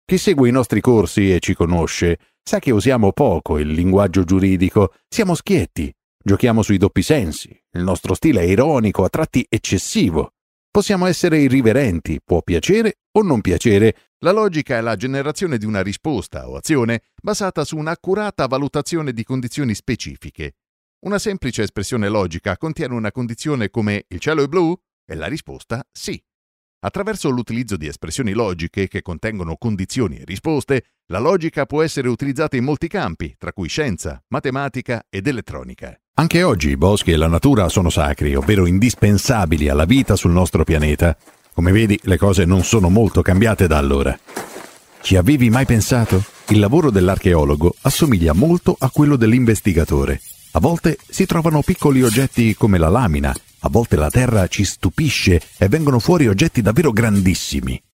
E-learning
My voice is deep, mature, warm and enveloping, but also aggressive, emotional and relaxing.